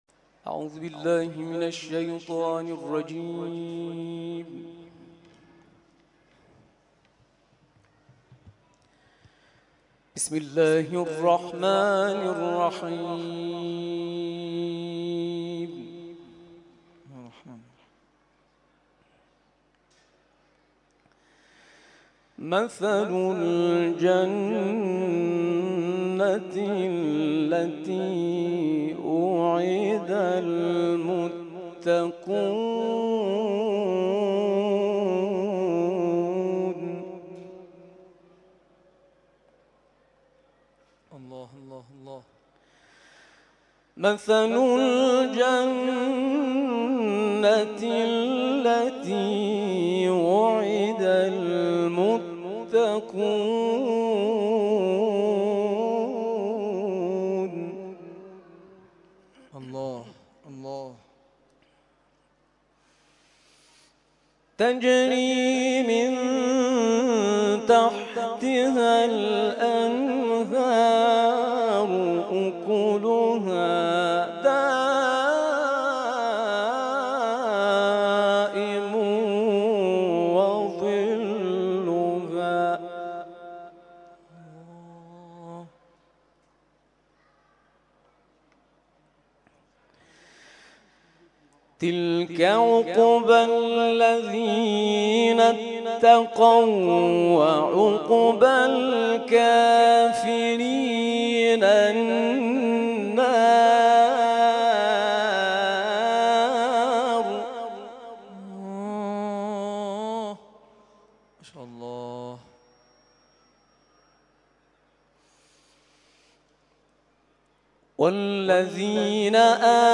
تلاوت‌های محفل قرآنی سوم شهریور آستان عبدالعظیم(ع)